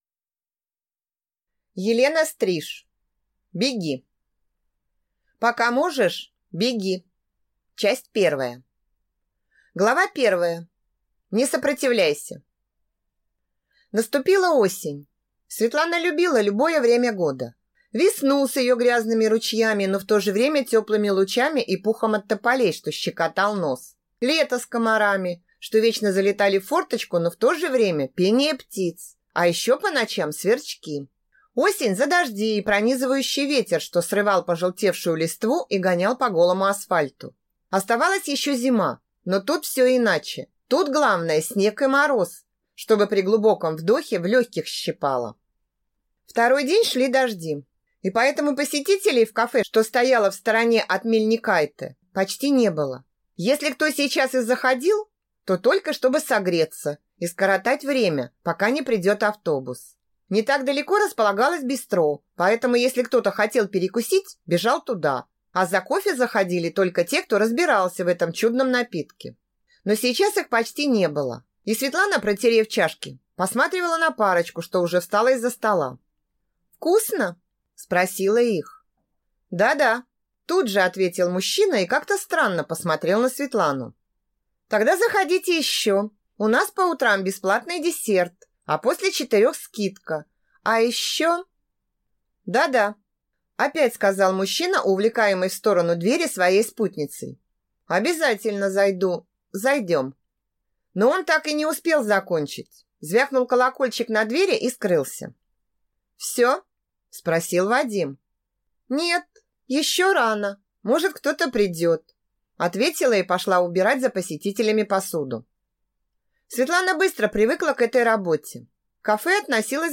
Аудиокнига Беги | Библиотека аудиокниг